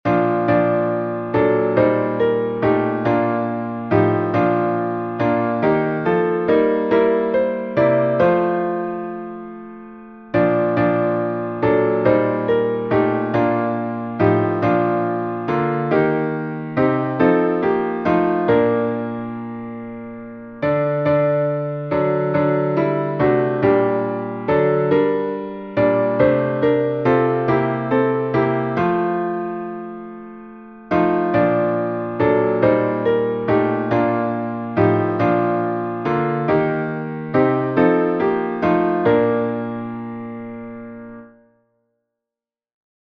salmo_7B_instrumental.mp3